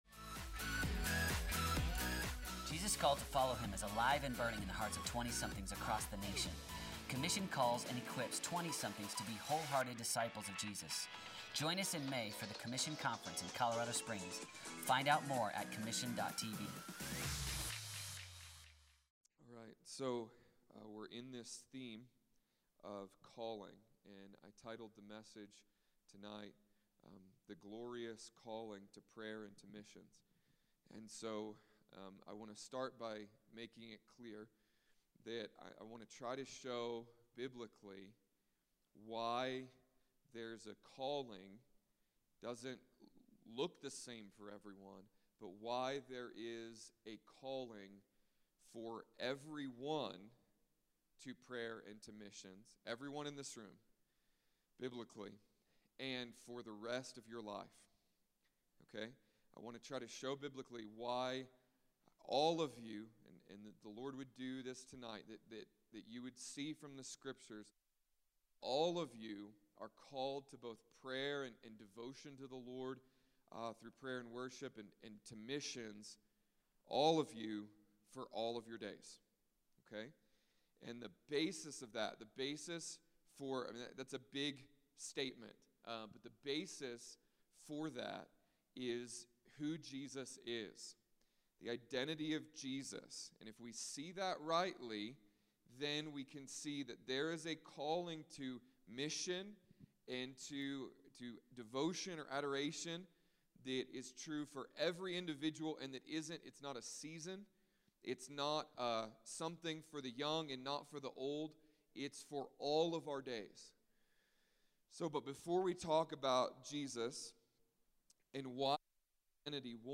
Spoken on November 5th, 2014 at the weekly Commission gathering in Colorado Springs.